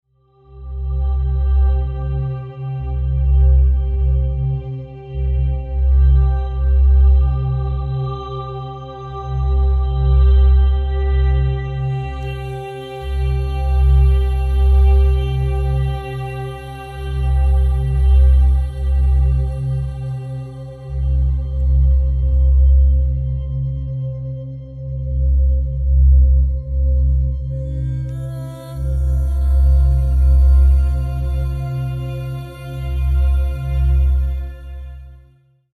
Brain waves 🧠, help you sound effects free download
✨ These brain waves are often present during sleeping and calming down and are great to fight stress and anxiety states.